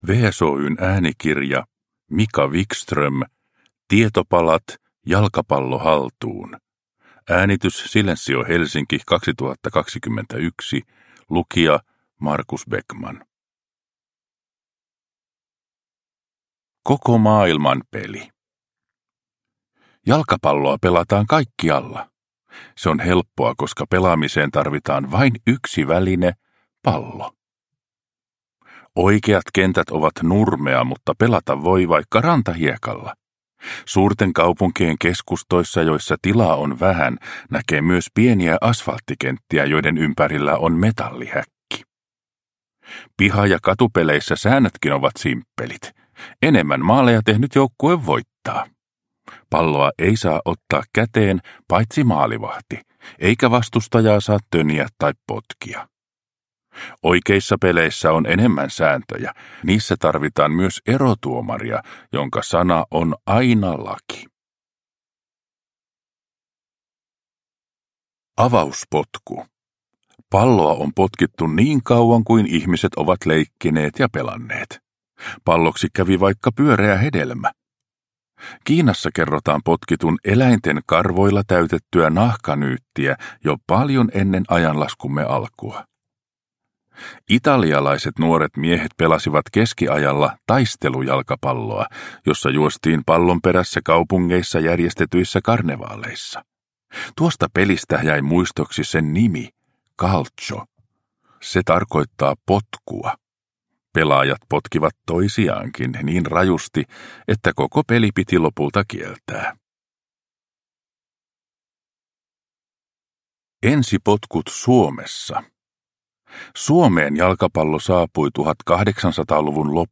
Tietopalat: Jalkapallo haltuun – Ljudbok – Laddas ner